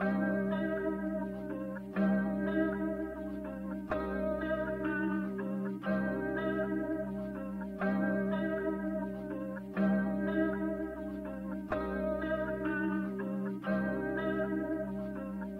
GANG GANG MELODY (123 BPM G Minor)